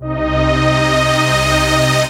VEC3 FX Athmosphere 27.wav